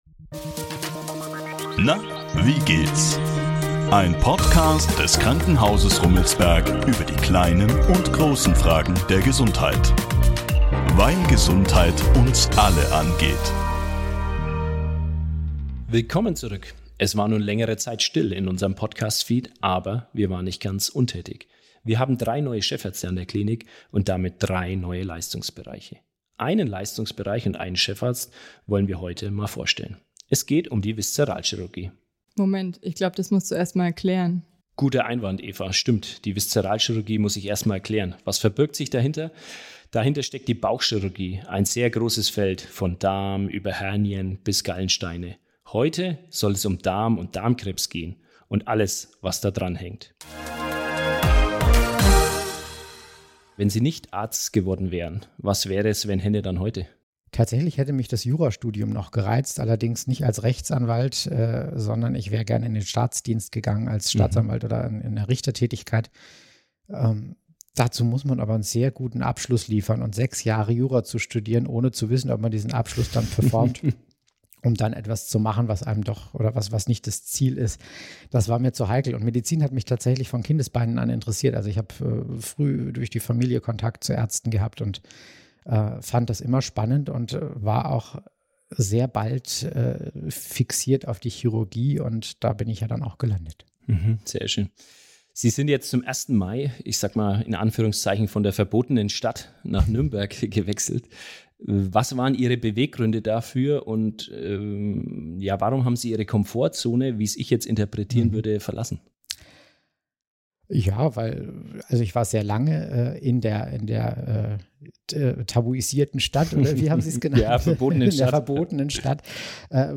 Ein kurzweiliges Gespräch mit einem Fachmann, der auch mit Vorurteilen umgehen kann!